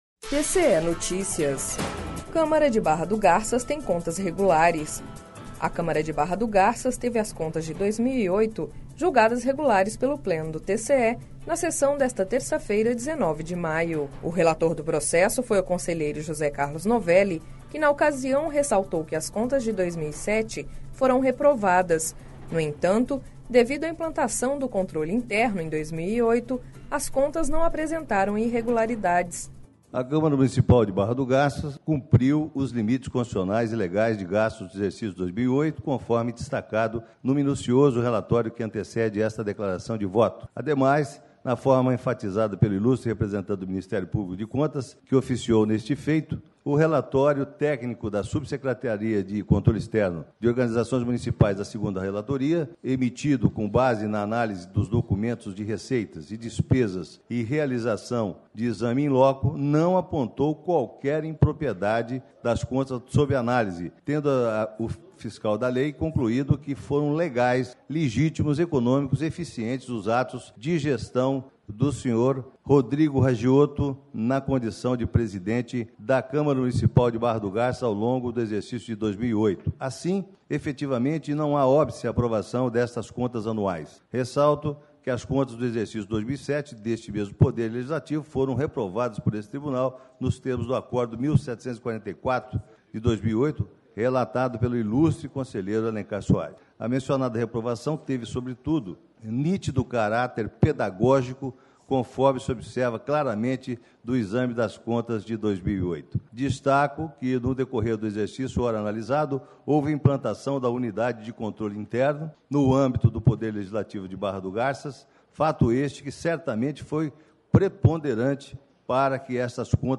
Sonora: José Carlos Novelli - conselheiro do TCE-MT